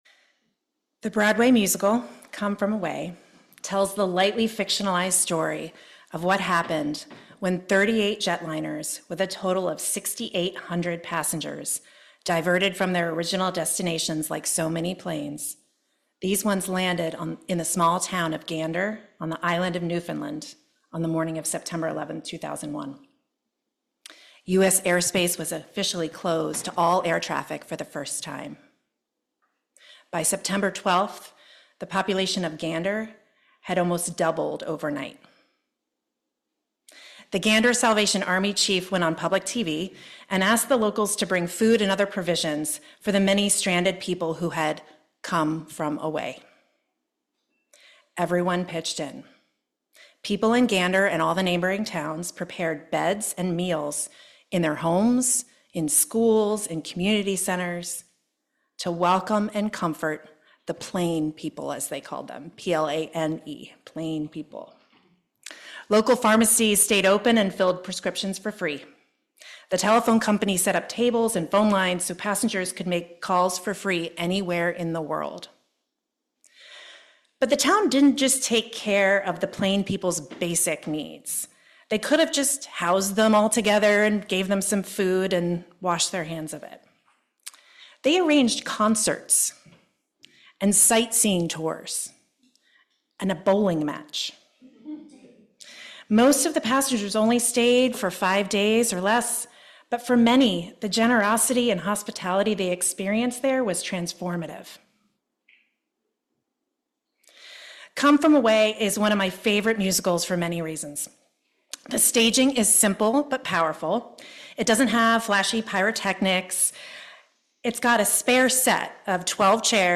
This sermon explores the concept of radical hospitality through the lens of the Broadway musical Come From Away, which depicts the true story of how Gander, Newfoundland, cared for thousands of str…